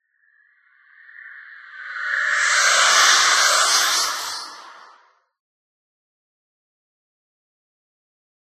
ghost.ogg